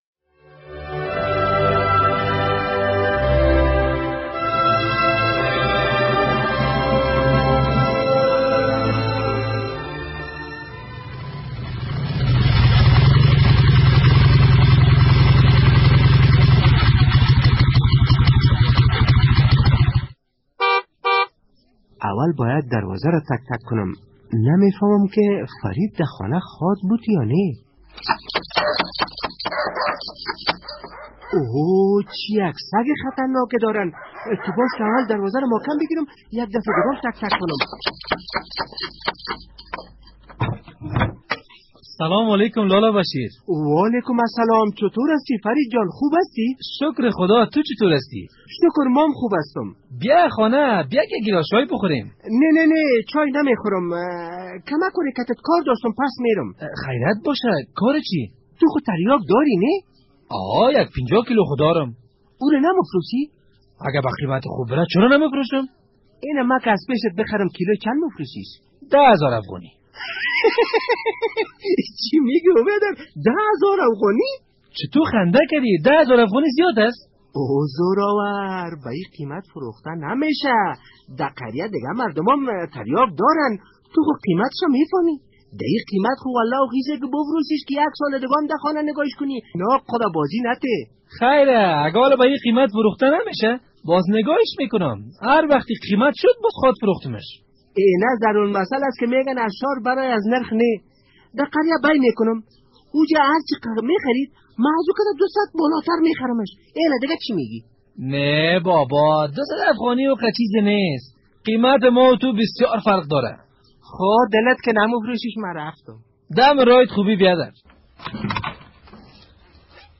درامه: فوزیه نصف شب یک صدای نا آشنا را می‌شنود